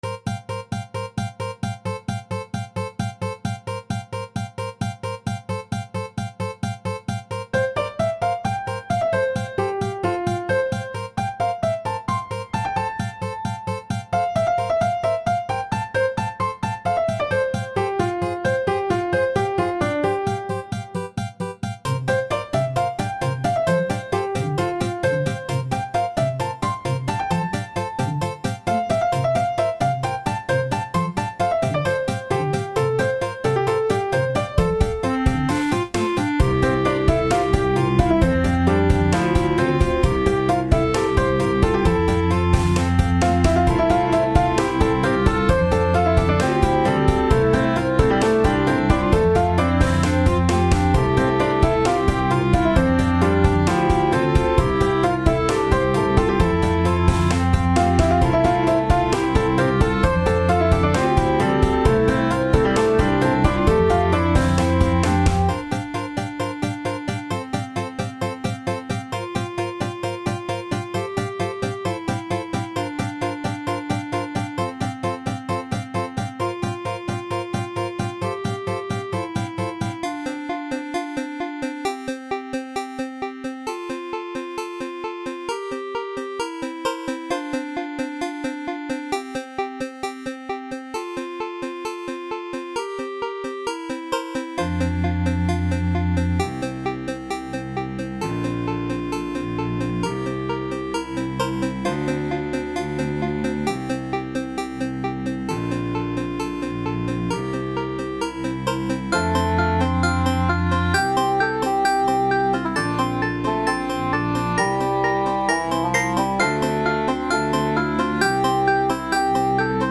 that piano is the cutest thing ever.